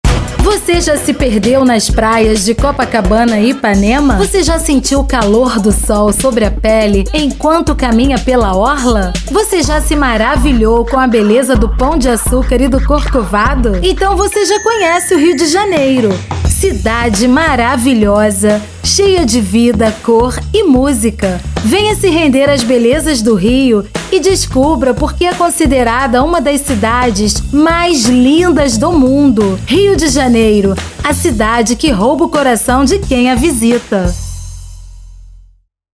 Sotaque Carioca RJ: